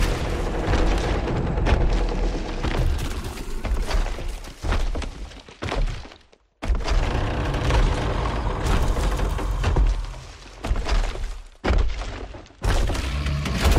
Download Free Wood Wraith Monster Sound Effects
Wood Wraith Monster